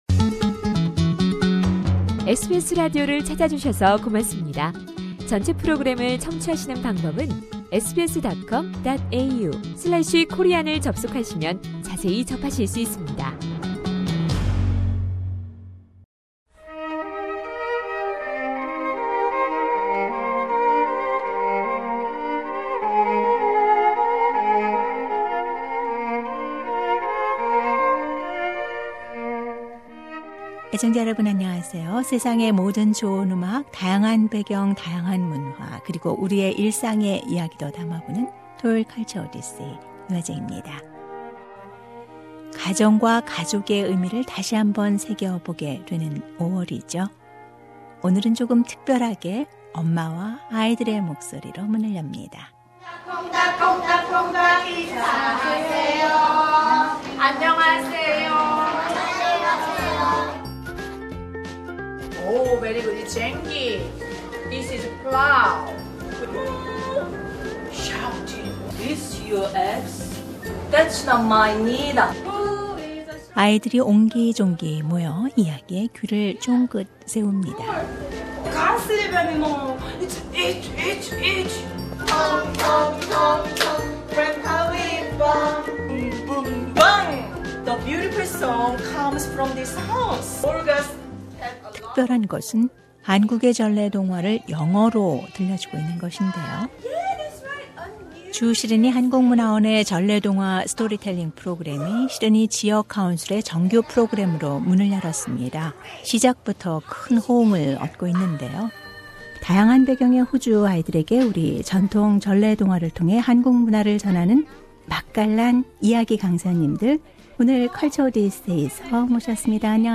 활동 체험담과 한국 전래동화 '햇님 달님' '혹부리 영감'을 영어 동화 구연으로 들어 봅니다.